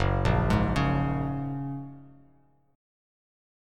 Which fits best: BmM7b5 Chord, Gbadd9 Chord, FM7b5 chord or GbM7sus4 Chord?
Gbadd9 Chord